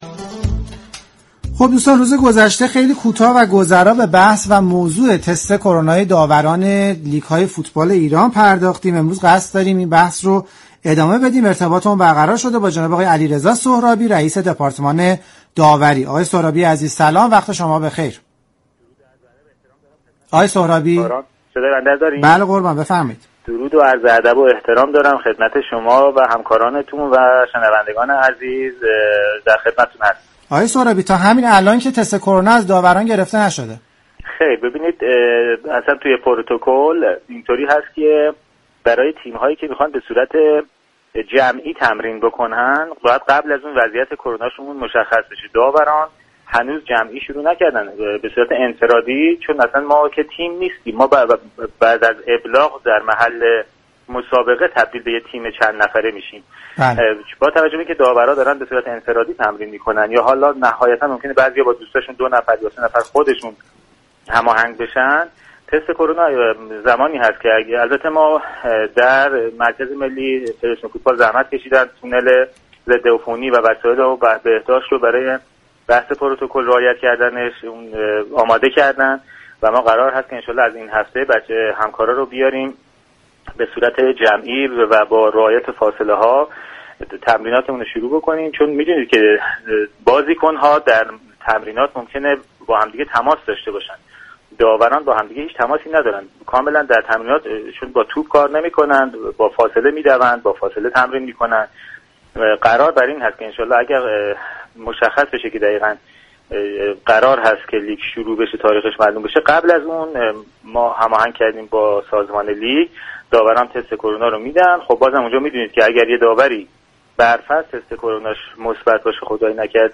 برنامه زنده "از فوتبال چه خبر؟" هر روز به جز جمعه ها ساعت 16 به مدت 55 دقیقه از رادیو ورزش پخش می شود.